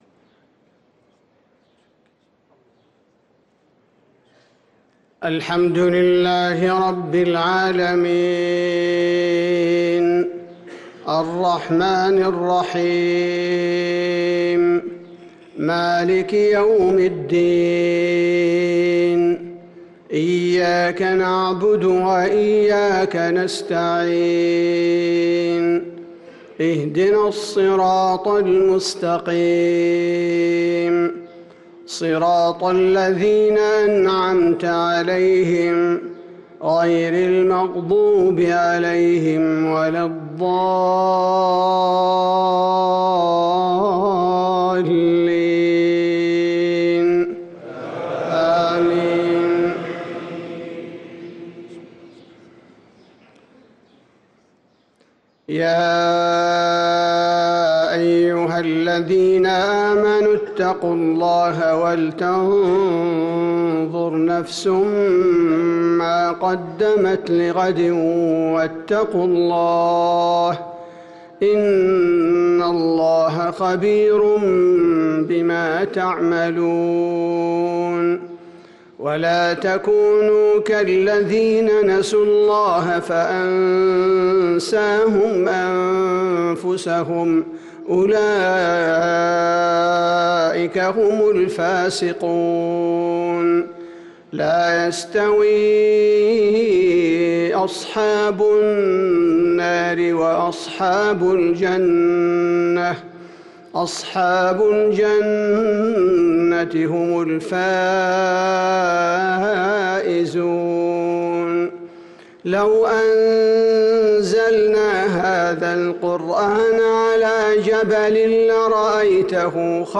صلاة المغرب للقارئ عبدالباري الثبيتي 7 شعبان 1445 هـ
تِلَاوَات الْحَرَمَيْن .